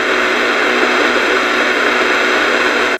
Radio Static
Crackling radio static with intermittent signal fragments and white noise texture
radio-static.mp3